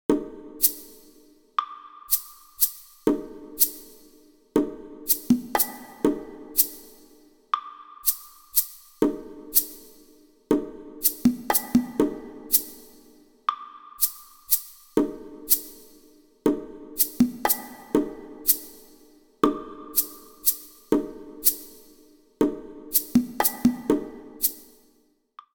旅の楽団が演奏する、という設定なので、ドラムではなくパーカッションを使用します。
当たり障りのない所で、ボンゴやウッドブロック、シェイカー、タンバリン等が良いと思い、それでシーケンスを組んでいます。
１番サビに入るまでは静かに、そこから徐々に盛り上がる感じの構成にします。
luflen-percass-4.mp3